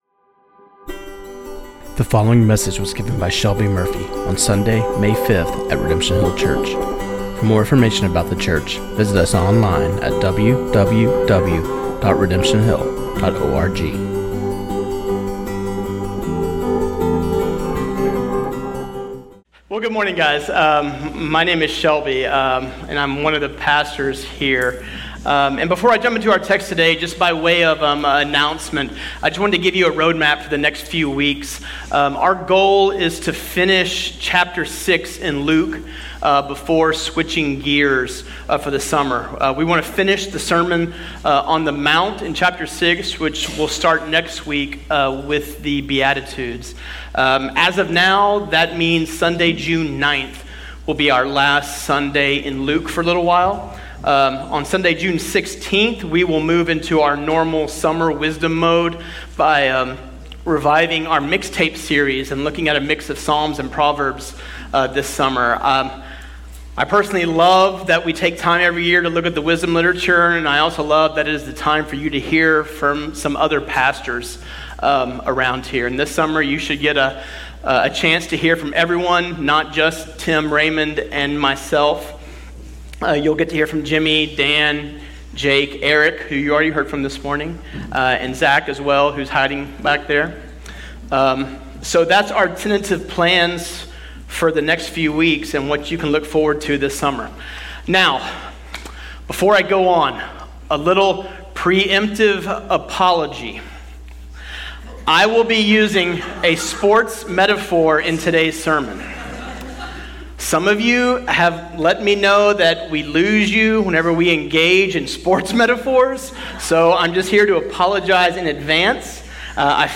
This sermon on Luke 6:12-16